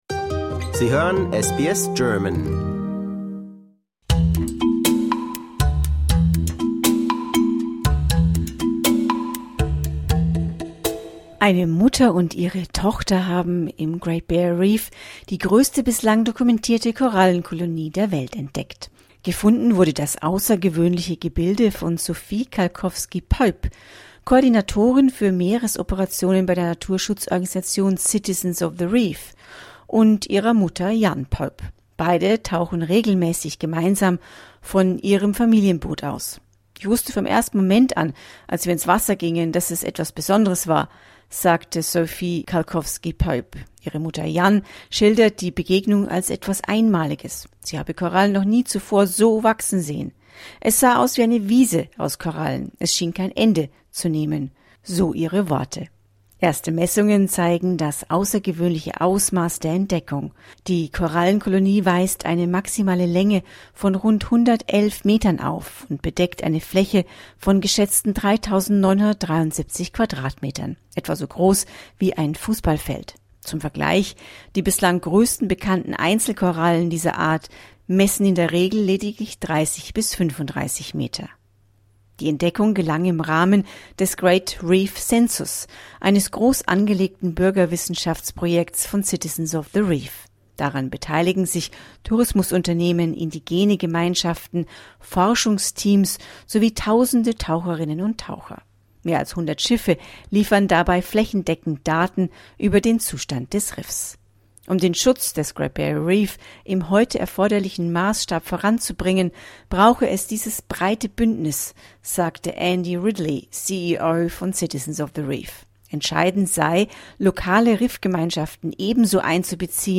The discovery shows how much of the Great Barrier Reef remains unexplored — but at the same time falls during a period of massive coral loss due to climate change, heat stress and environmental pollution. A report on hope, research, and the fragile future of a unique ecosystem.